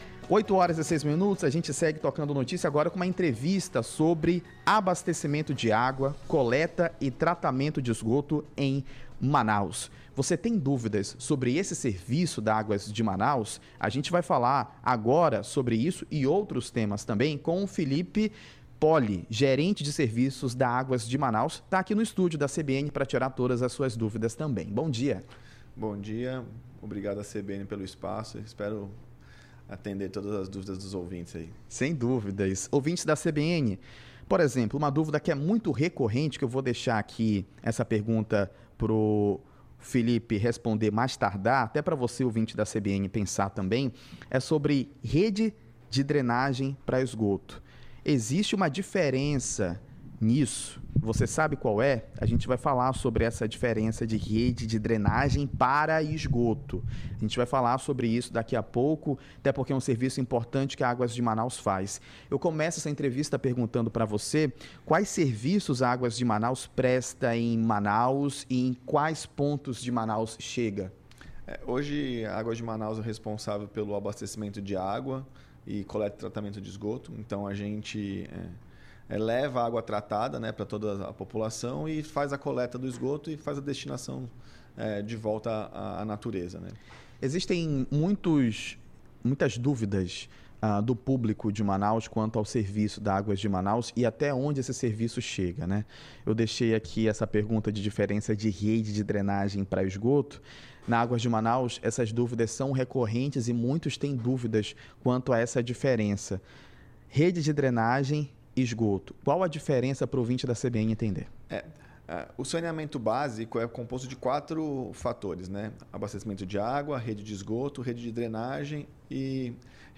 aguas-de-manaus-entrevista.mp3